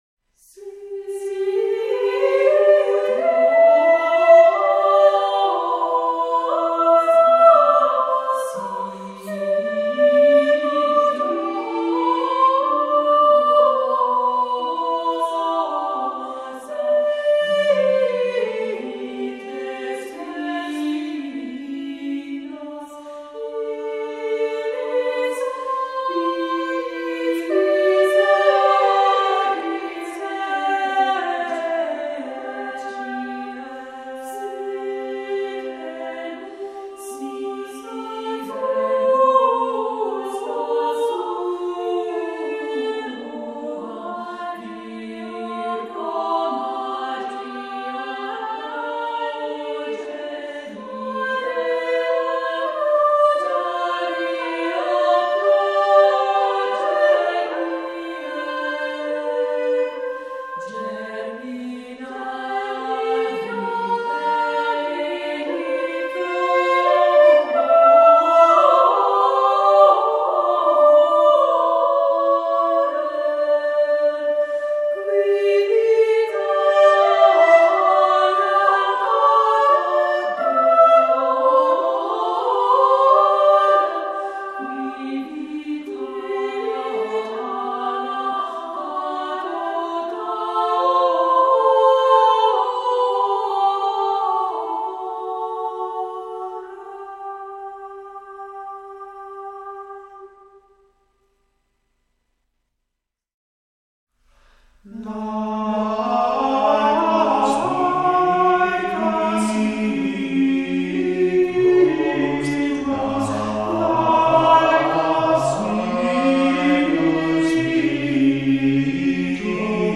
Voicing: Two-part choir, a cappella